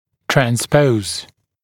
[træn’spəuz][трэн’споуз]транспонировать